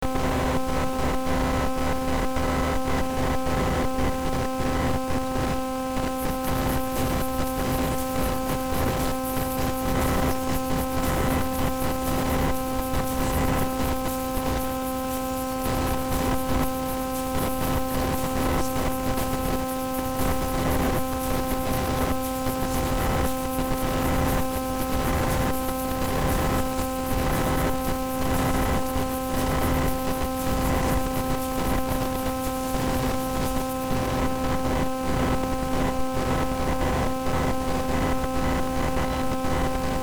So I tried this: Connected 2 plugs to transmit sound